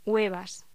Locución: Huevas